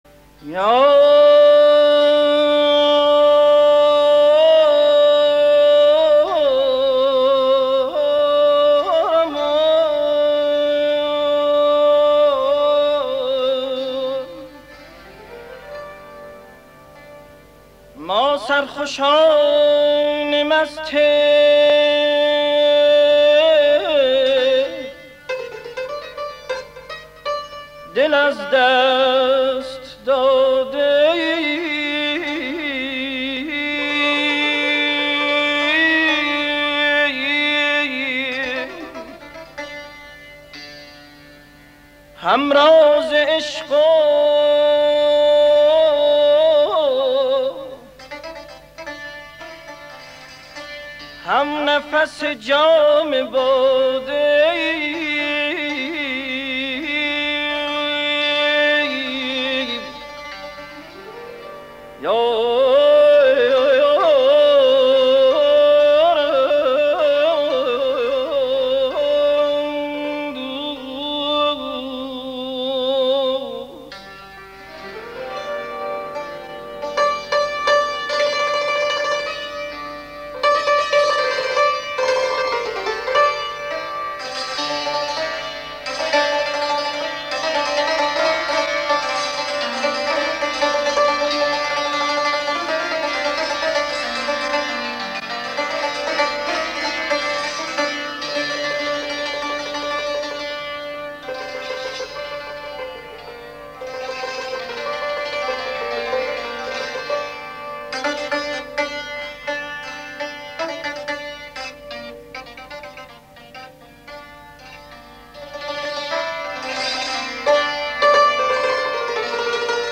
15 آذر سال 1346 برای اولین بار صدای محمدرضا شجریان از رادیو ایران بخش شد. این اجرا در تابستان قبل ضبط شده بود و شجریان در آن سالها به دلیل مخالفت پدر با آواز خوانی پسرش در رادیو، با نام «سیاوش بیدگانی» می خواند.
فضای رادیو در آن سالها مطلوب هنرمندان نبود با این حال مرحوم داوود پیرنیا که یکی از بهترین برنامه های موسیقی رادیو را اداره می کرد، از خواننده جوان دعوت کرده بود تا در رادیو ایران بخواند و شجریان اولین آواز خود را با سنتور مرحوم رضا ورزنده در رادیو ضبط کرد.